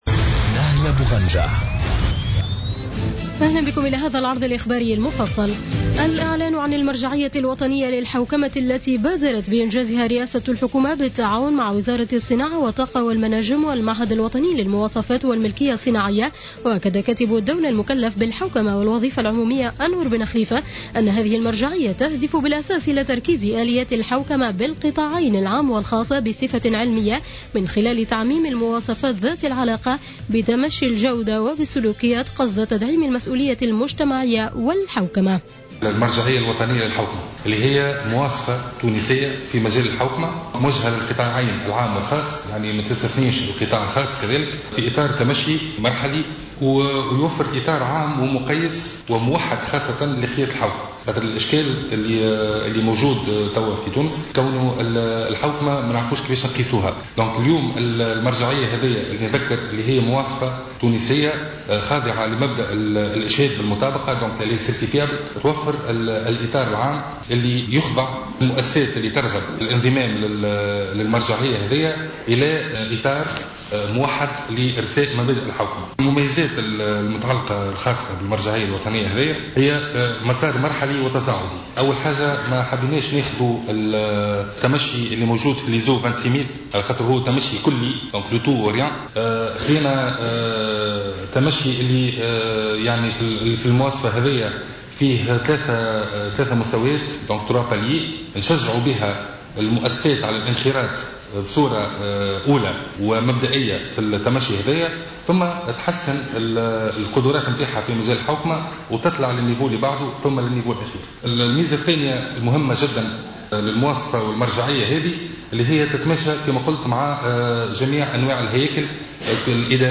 نشرة أخبار السابعة مساء ليوم الاربعاء 17-12-14